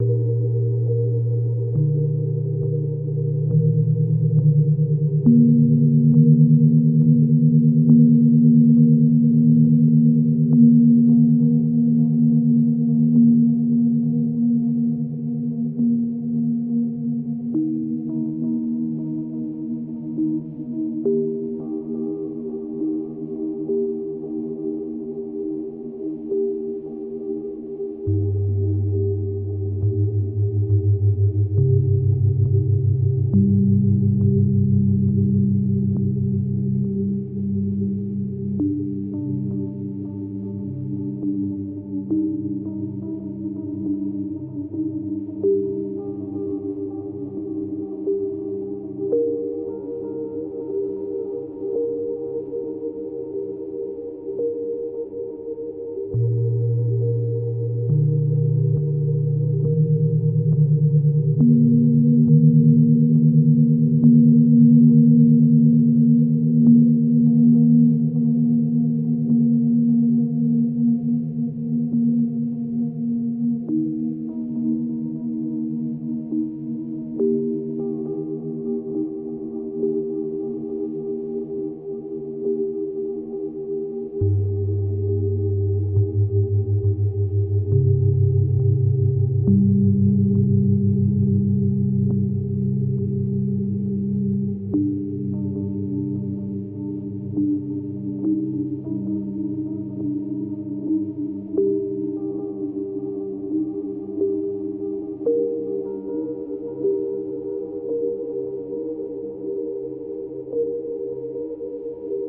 Speed 30%